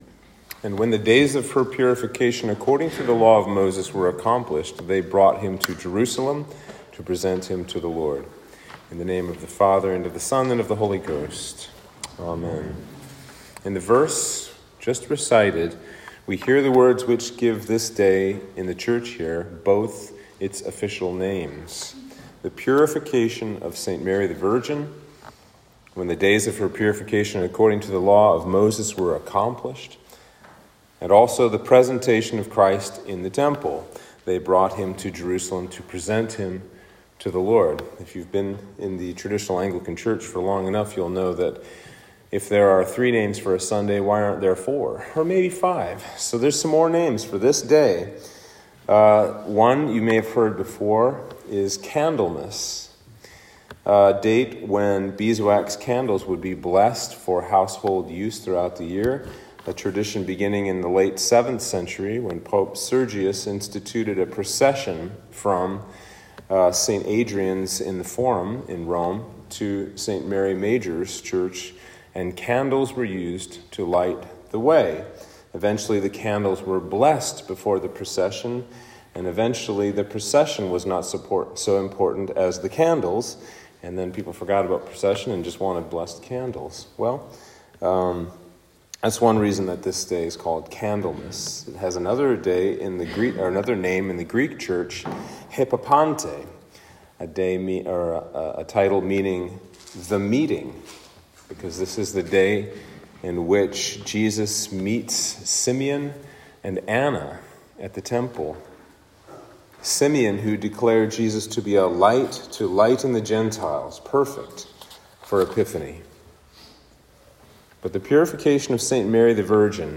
Sermon for Presentation of Christ at the Temple/Purification of St. Mary the Virgin (Feb 2)